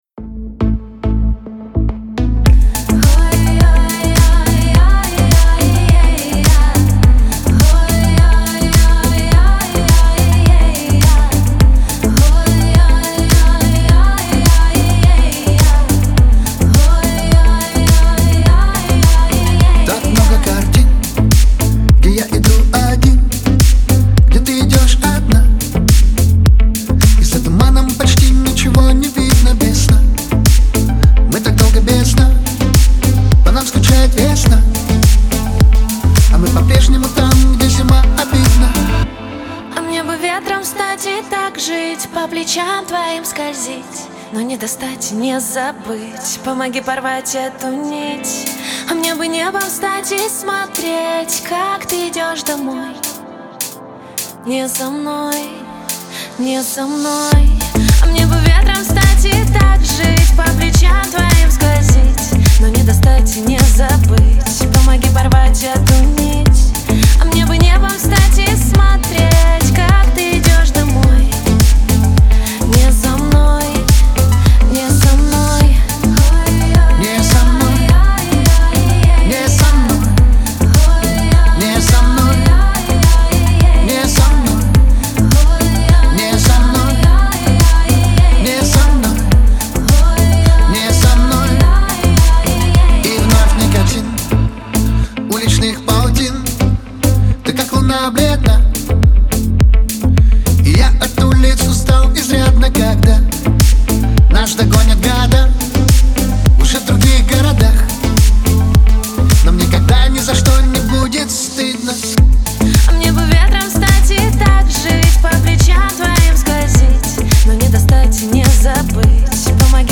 это трек в жанре поп-рок